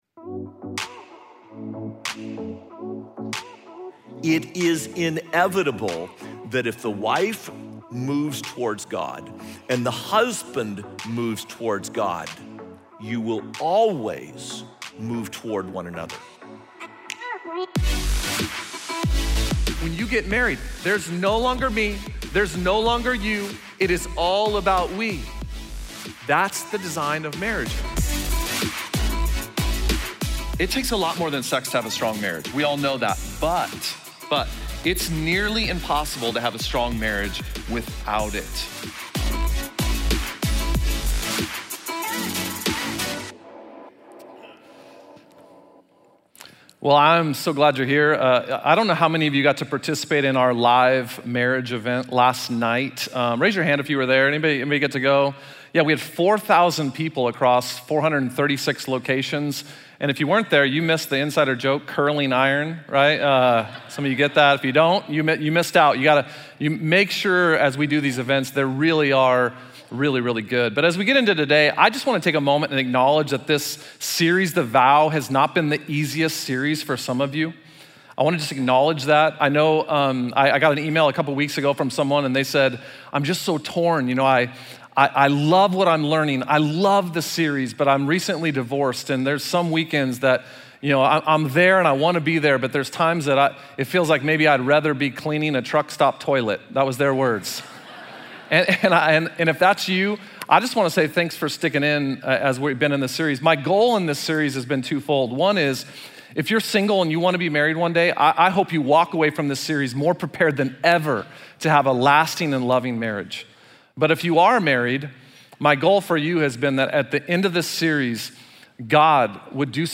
Missed church service this weekend or want to hear the message again?